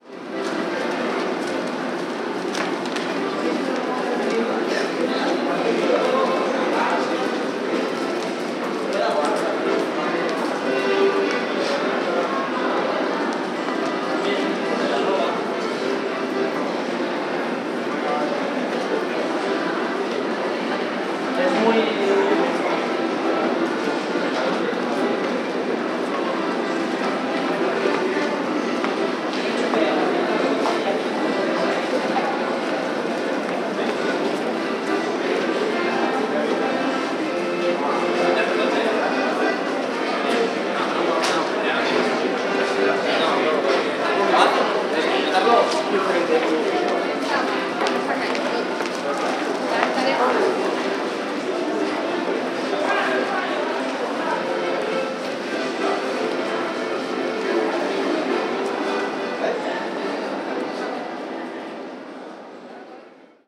Pasillo del Metro con música
pasillo
metro
música
subterráneo
Sonidos: Gente
Sonidos: Transportes
Sonidos: Ciudad